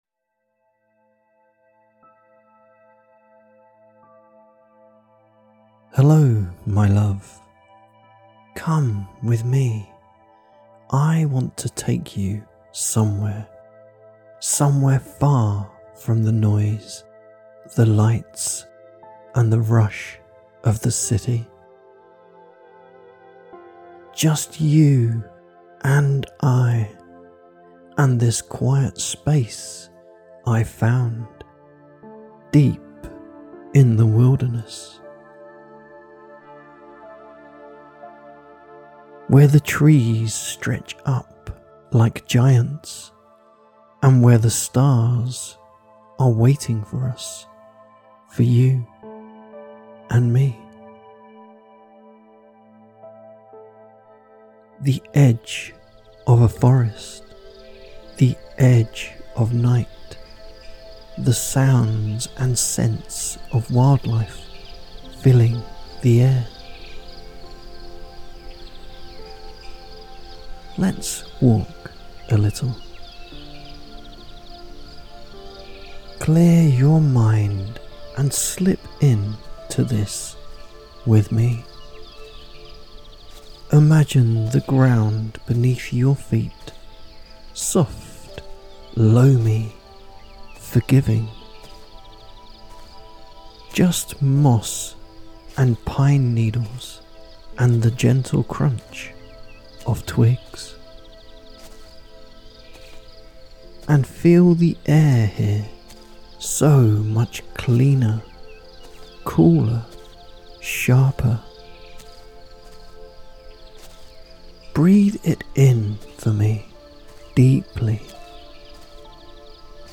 Campfire Sleep - Deep sleep hypnosis
– A hypnotic sleep file that guides guides you into a deeply immersive night-time trance, surrounded by quiet nature, the rhythmic crackle of flame, and the comforting presence of a loving companion – your foxy friend.